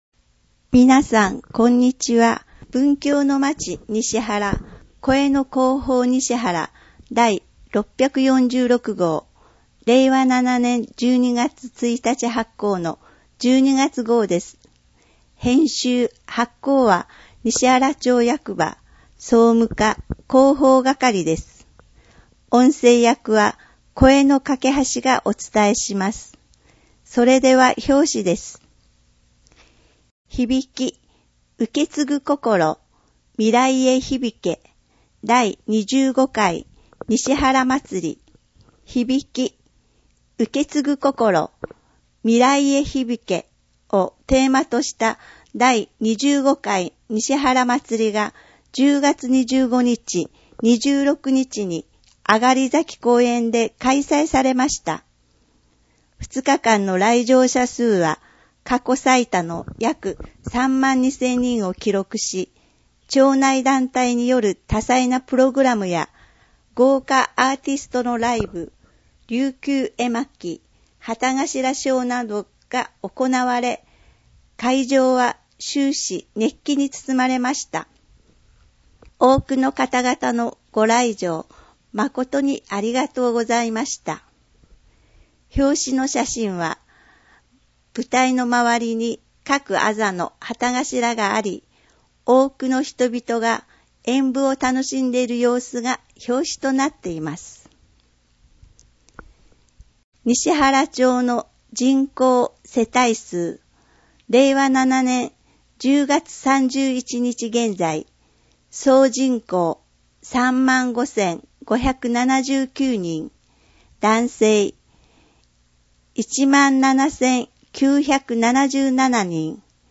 声の広報にしはらは、広報にしはらの情報を音声でお届けしています。
音訳ボランティアサークル「声の広報かけはし」が録音しています。